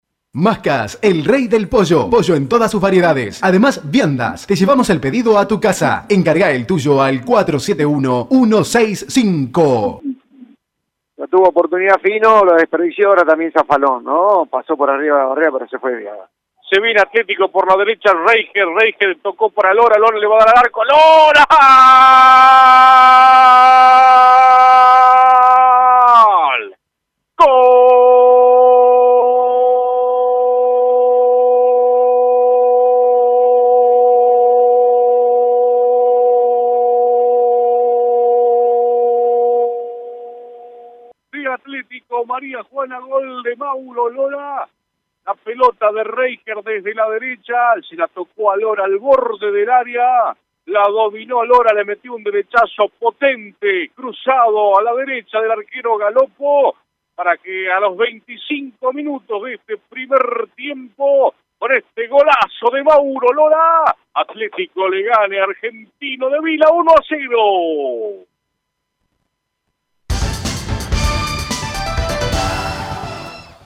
GOLES: